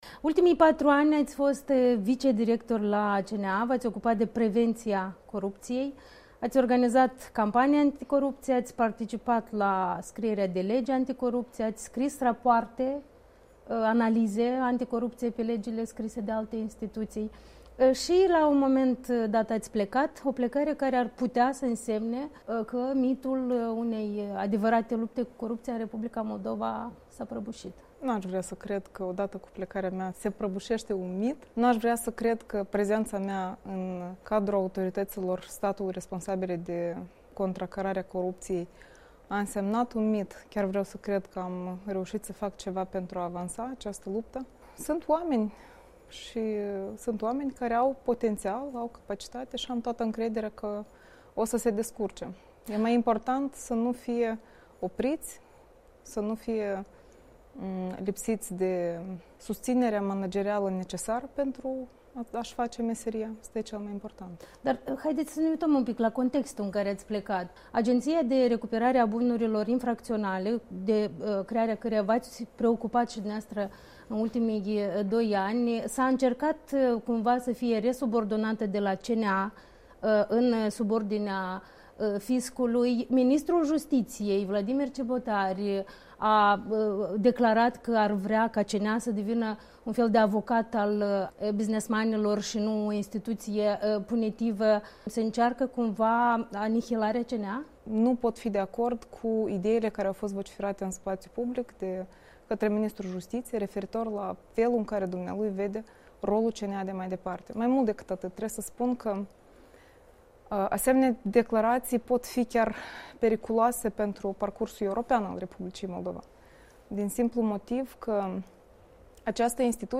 Interviu cu fosta dirctoare adjunctă a Centrului Național Anticorupție.